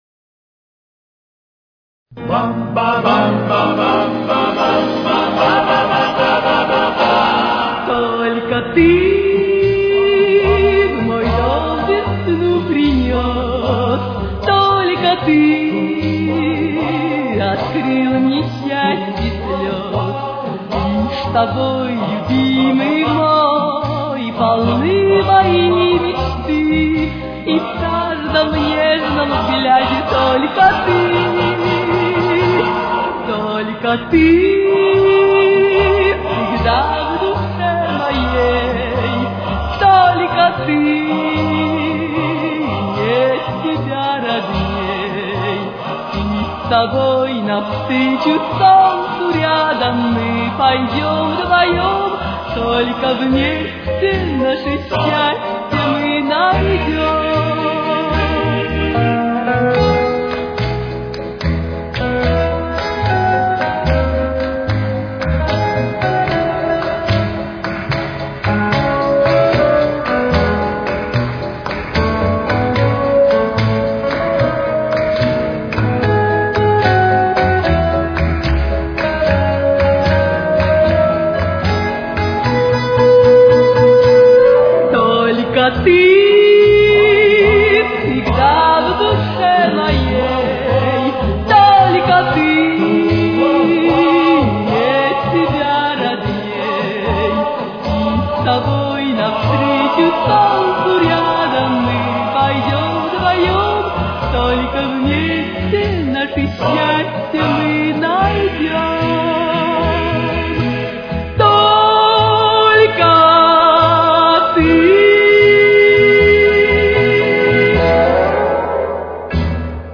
Темп: 77.